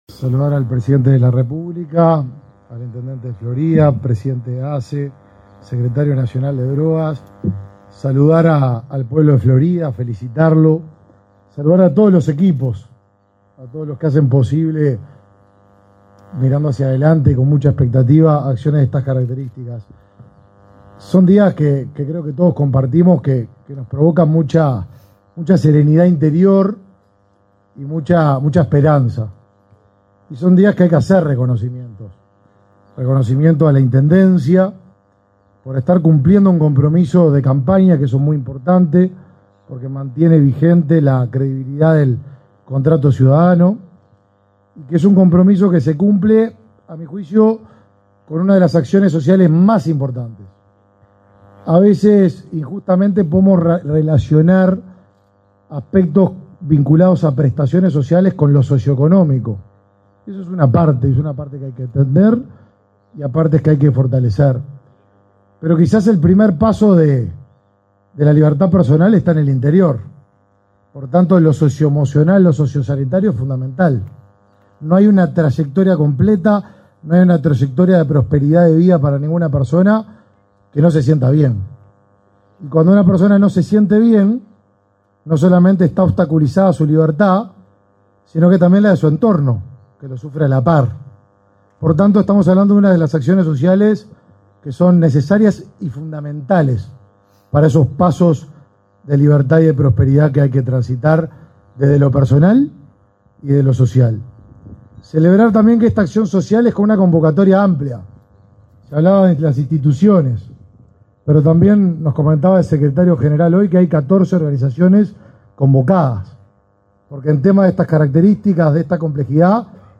Palabras del ministro del Mides, Martín Lema
Palabras del ministro del Mides, Martín Lema 27/09/2023 Compartir Facebook X Copiar enlace WhatsApp LinkedIn El presidente de la República, Luis Lacalle Pou, participó, este 27 de setiembre, en la inauguración del centro diurno para personas que padecen consumo problemático de drogas, en Florida. En la oportunidad, el titular del Ministerio de Desarrollo Social (Mides), Martín Lema, realizó declaraciones.